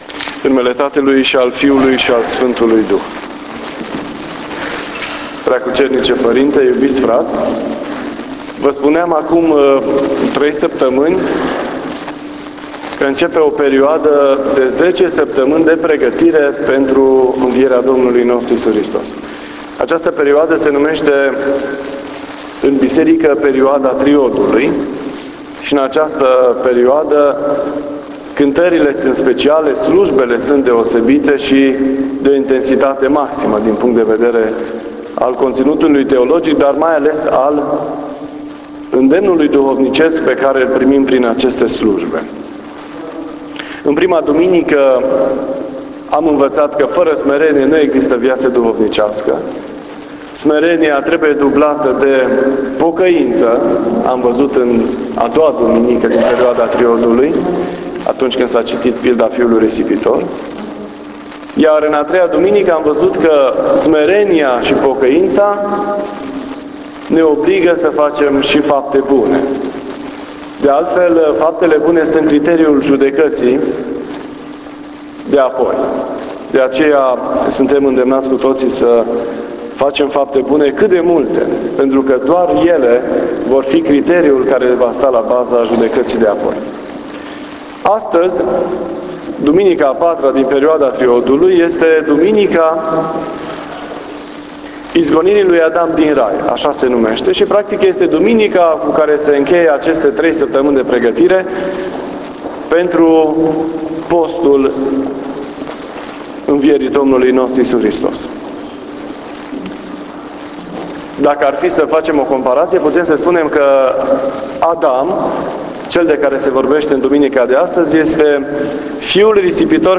Despre IERTARE (18 februarie 2018) Posted on February 18, 2018 admin Posted in predici Cuvânt la Duminica Izgonirii lui Adam din Rai.